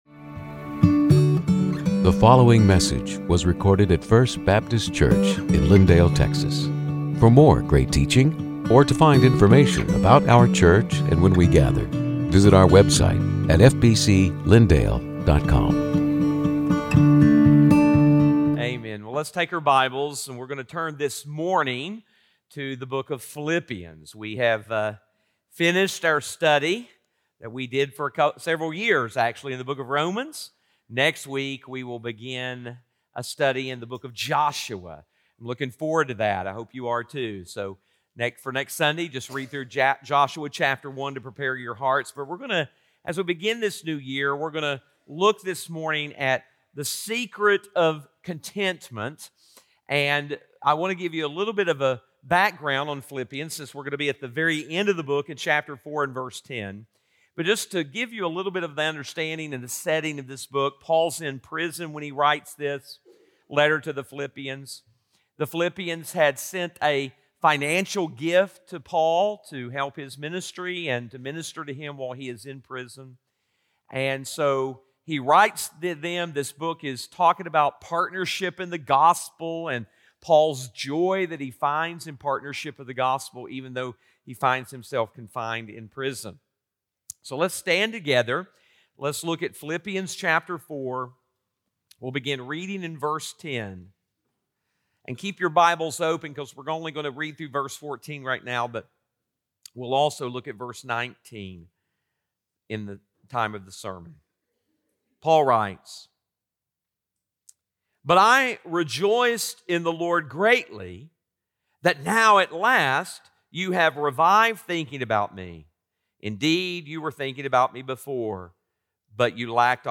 Sermons › Contentment